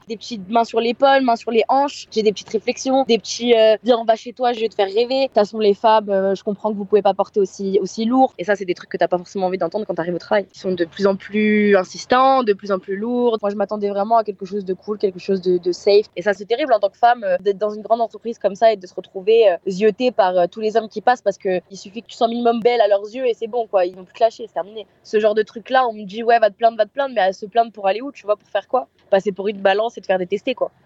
son-harcelement-femme-au-travail-64037.mp3